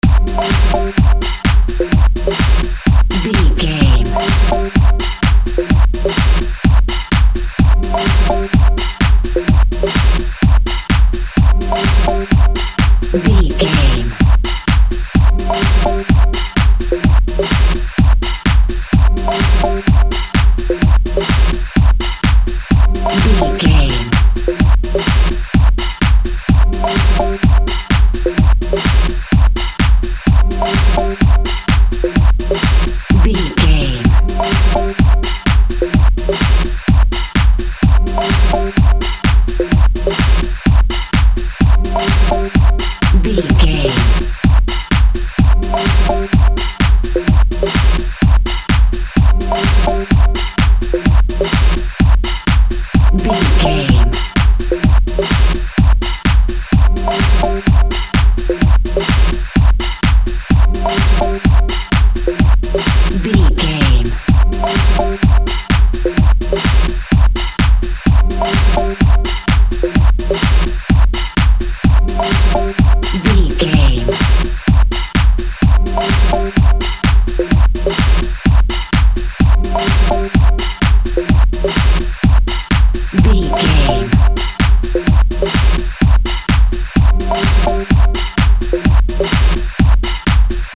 Fusion Dance Music.
Atonal
groovy
smooth
futuristic
uplifting
drums
synthesiser
Drum and bass
break beat
electronic
sub bass
synth lead
synth bass